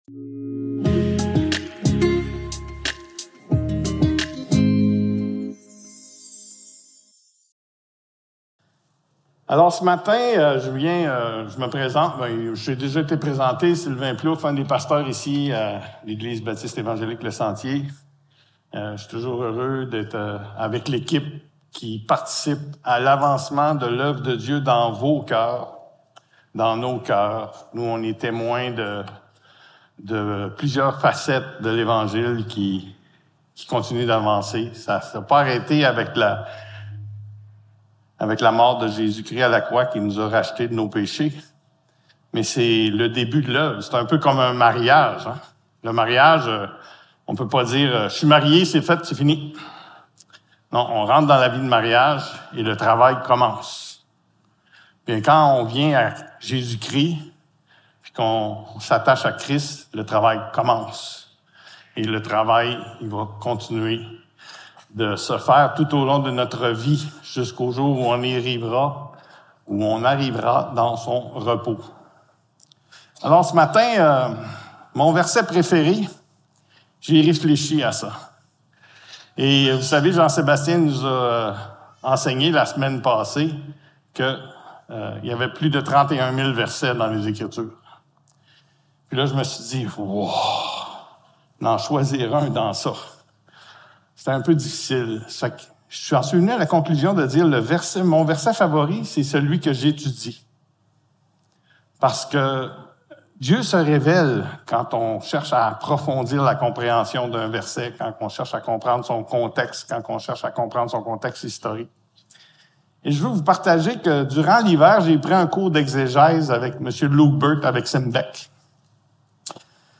Passage: 1 Jean 1.1-4 Service Type: Célébration dimanche matin